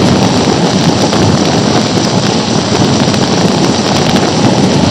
fireball_idle.ogg